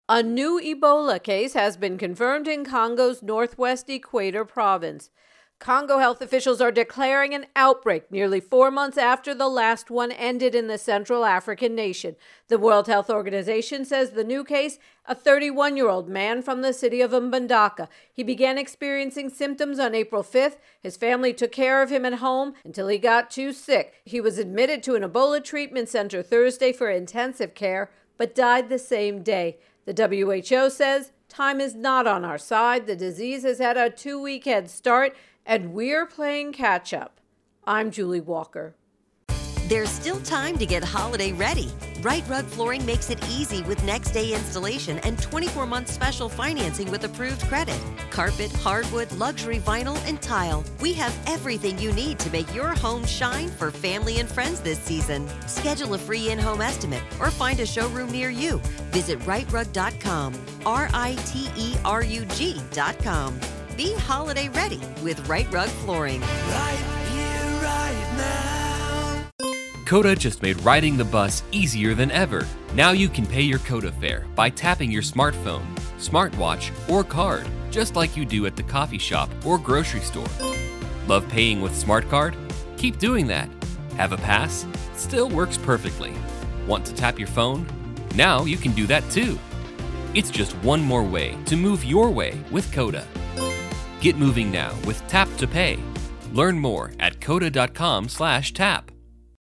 Congo Ebola intro and voicer